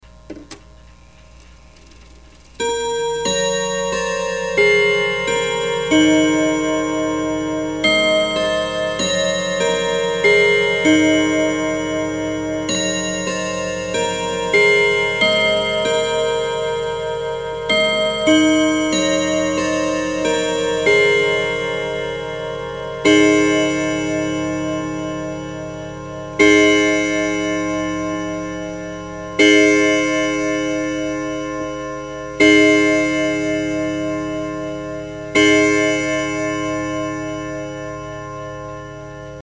It has a jeweled escape wheel, strike/silent, and chime selector switch, fast/slow through the dial at the 12, automatic chime reset, 'German Silver' rods, chain hammer lifts, and a good quality pendulum.
To hear the clock play Trinity, click the "Hear it!" link above.